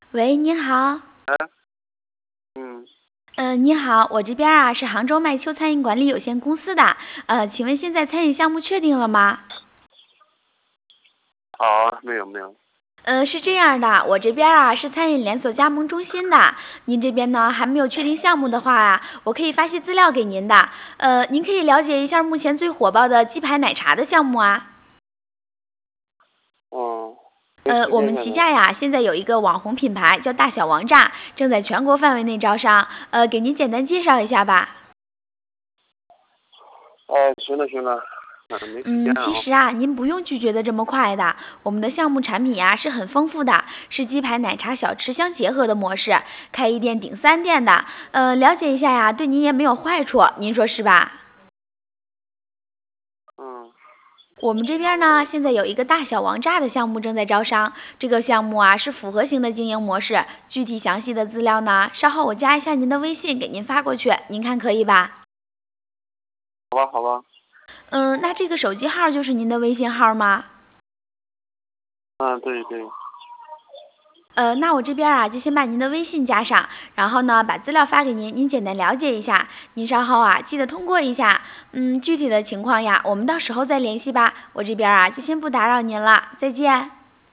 通过专业级真人式模拟销售或客服专家，和客户进行多轮互动高效沟通交流，快速完成ABC类意向客户的筛选和分类